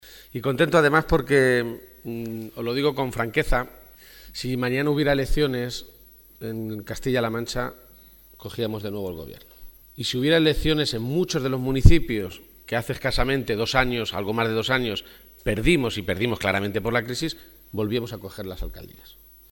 Momento de la intervención de García-Page